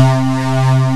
FUNK C4.wav